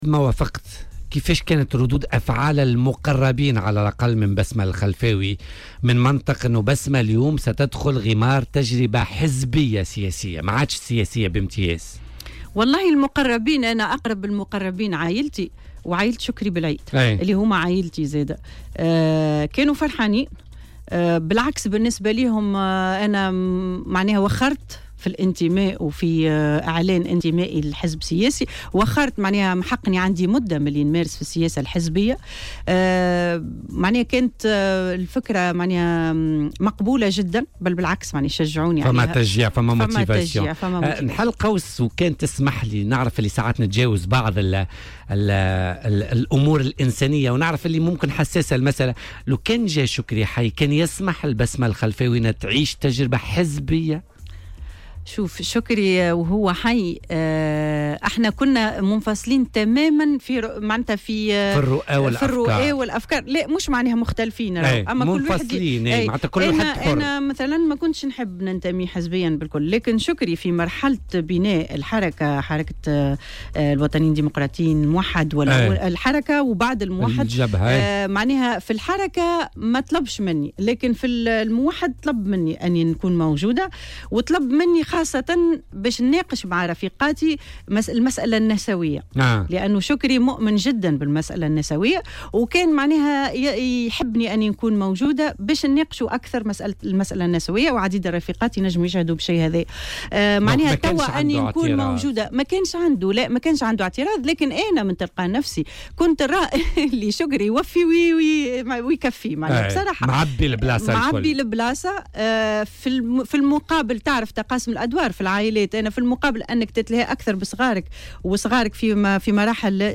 وأضافت في مداخلة لها اليوم في برنامج "بوليتيكا" على "الجوهرة أف أم" أنهم اعتبروا أنها تأخرت كثيرا في إعلان انضمامها إلى حزب سياسي والدخول إلى عالم السياسة.